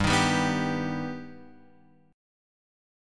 G+ chord {3 6 5 4 x 3} chord
G-Augmented-G-3,6,5,4,x,3.m4a